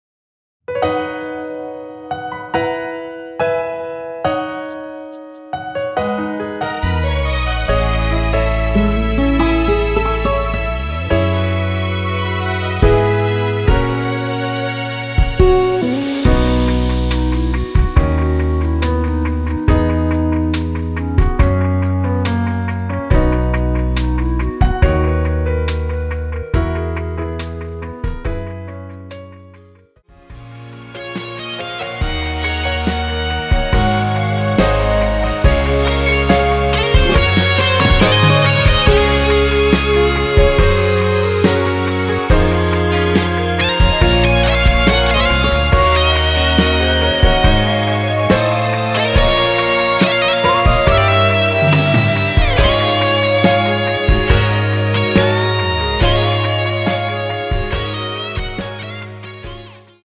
원키에서(-9)내린 멜로디 포함된 MR입니다.
앞부분30초, 뒷부분30초씩 편집해서 올려 드리고 있습니다.